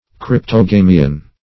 Cryptogamian \Cryp`to*ga"mi*an\ (kr?p`t?-g?"m?-an)